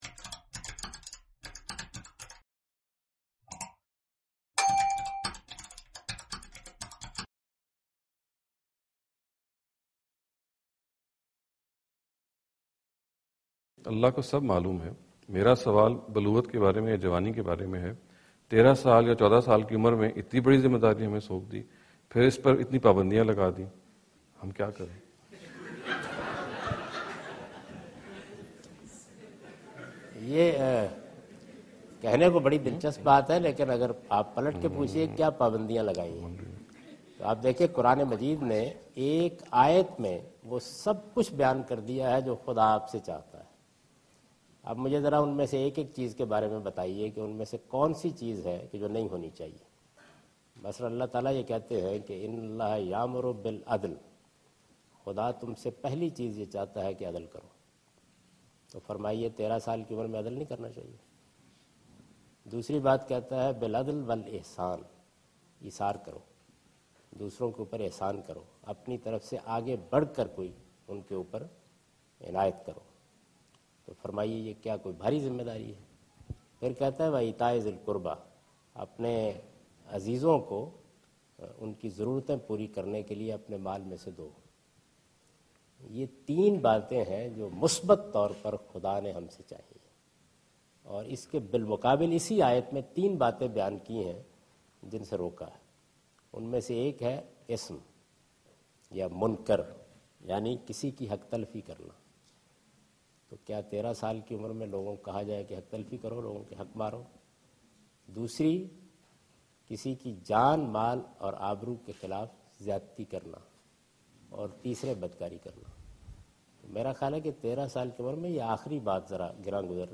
Category: English Subtitled / Questions_Answers /
Javed Ahmad Ghamidi answer the question about "Basic Islamic Teachings for Adolescents and Teenangers" during his visit to Georgetown (Washington, D.C. USA) May 2015.
جاوید احمد غامدی اپنے دورہ امریکہ کے دوران جارج ٹاون میں "بچوں اور نوجوانوں کے لیے ابتدائی اسلامی تعلیمات" سے متعلق ایک سوال کا جواب دے رہے ہیں۔